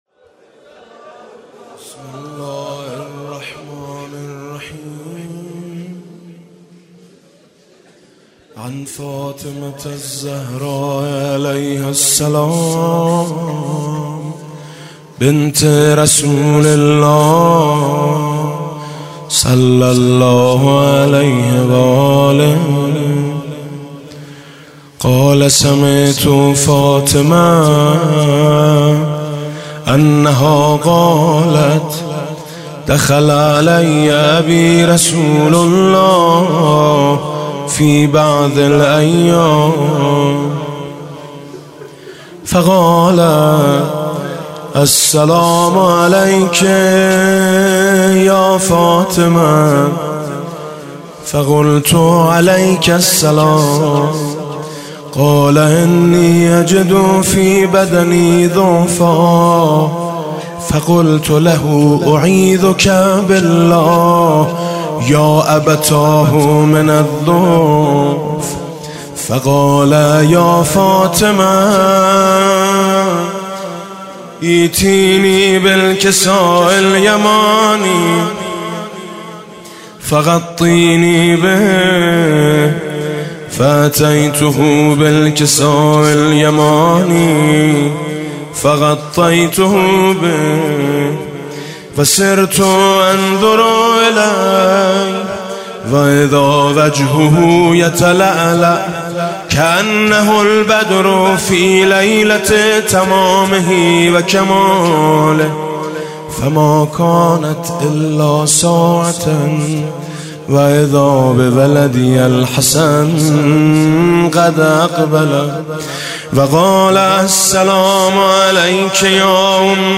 18 اردیبهشت 98 - هیئت میثاق با شهدا - حدیث شریف کساء